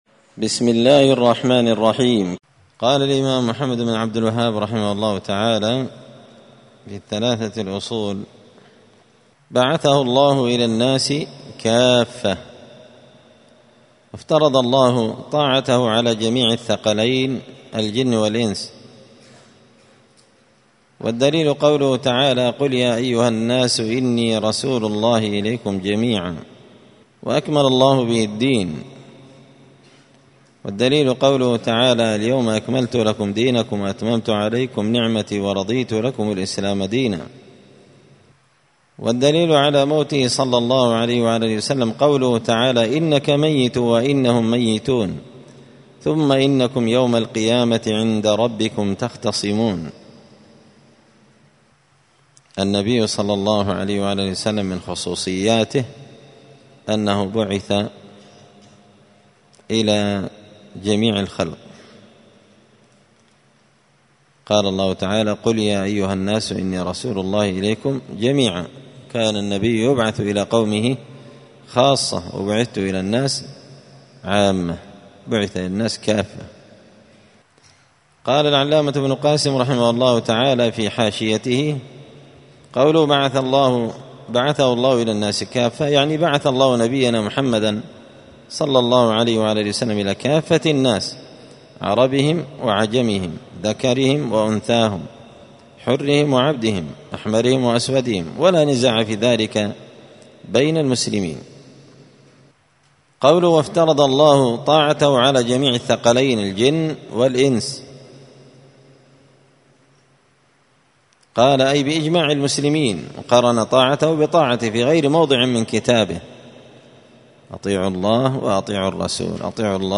دار الحديث السلفية بمسجد الفرقان بقشن المهرة اليمن
📌الدروس الأسبوعية